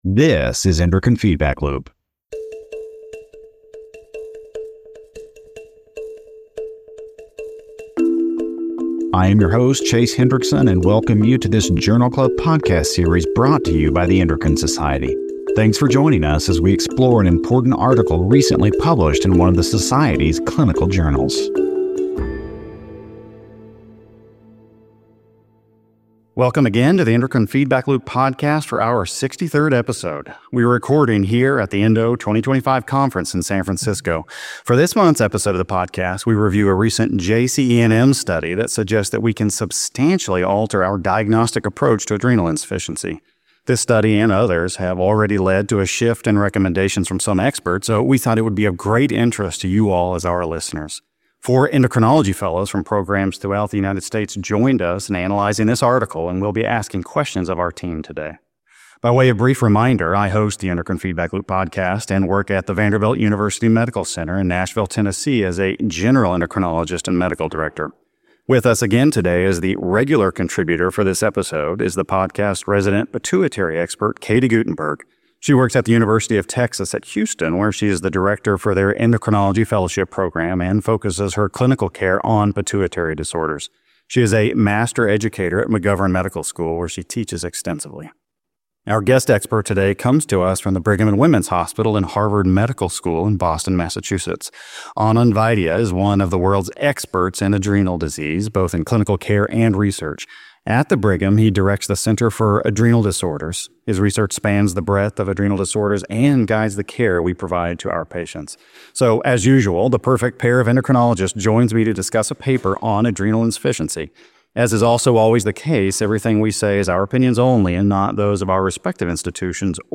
Can morning cortisol and DHEA-S levels serve as a first-line assessment for adrenal insufficiency? Find out in this month’s episode, recorded before a live audience at ENDO!
For our 63rd episode, we returned to the Endocrine Society’s annual meeting and recorded before a live audience at ENDO 2025 in San Francisco. We look at a study from The Journal of Clinical Endocrinology & Metabolism that suggests that we can substantially alter our diagnostic approach to adrenal insufficiency.
We have four senior fellows joining us to help analyze the article.